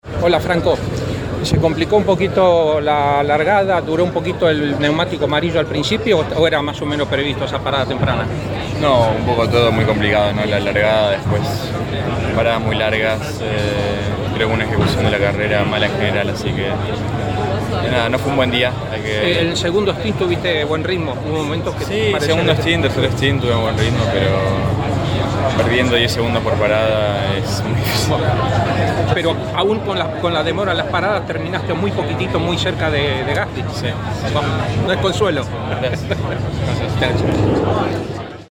AUDIO: Franco Colapinto en Campeones tras el GP de Hungría.